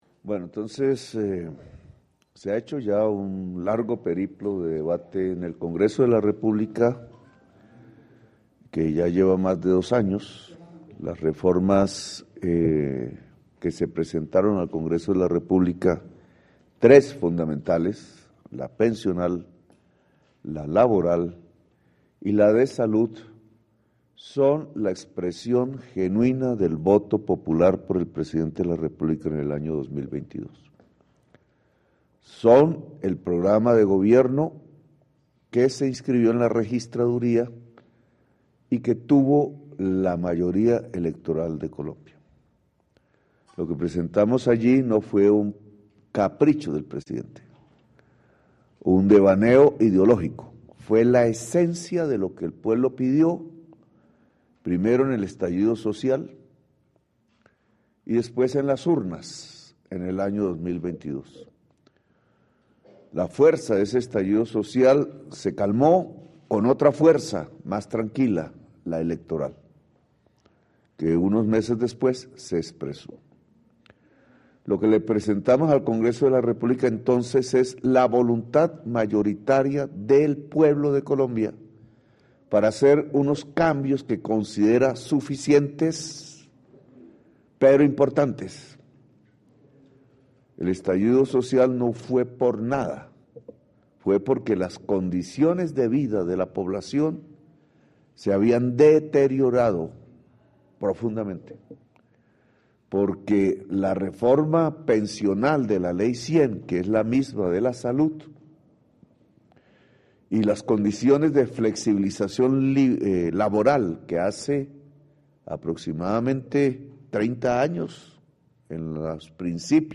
Discurso
Declaración del Presidente de la República, Gustavo Petro Urrego